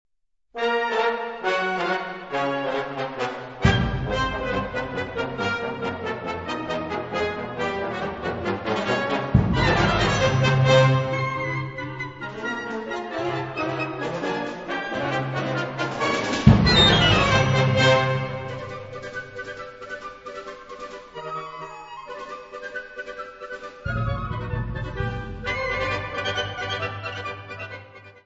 Catégorie Harmonie/Fanfare/Brass-band
Instrumentation Ha (orchestre d'harmonie)